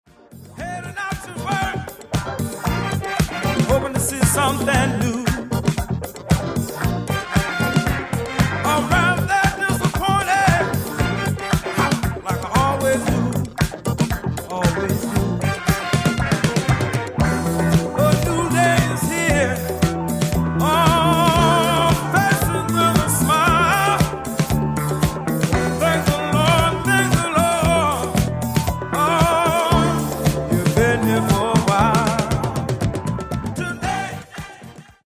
gruppo prettamente maschile
che sono un susseguirsi di sonorit� disco, soul e funk